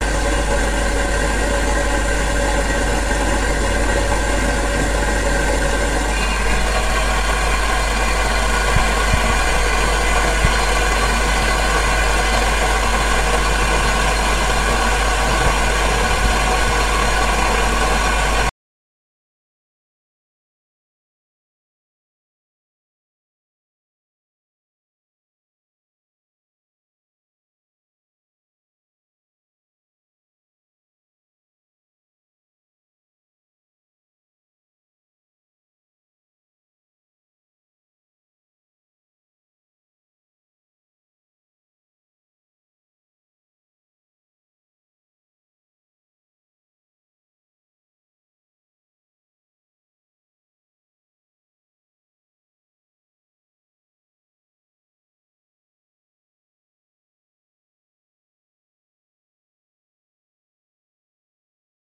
沸腾的水 3
描述：一个新的，有更好的声音
Tag: 沸水 气泡